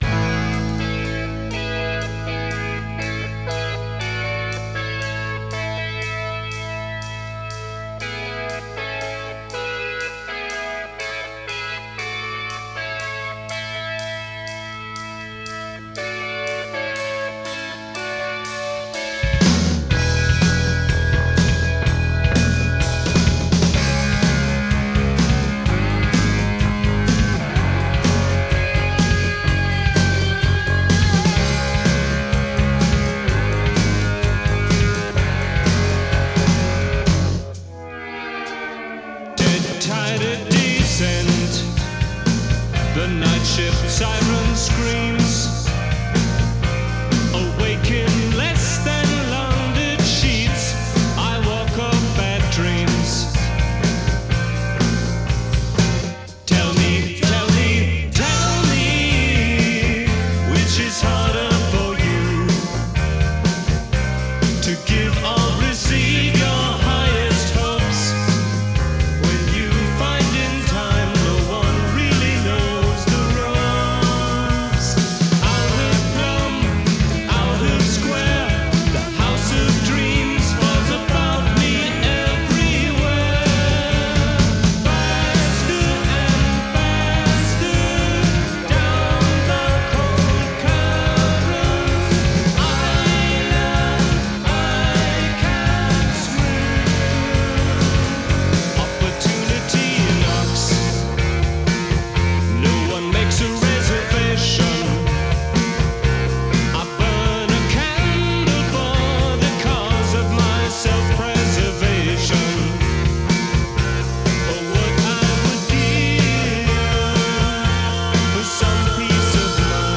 They were a small band with a big sound (round and loud)
on keyboards and vocals
bass guitar and vocals
drums and vocals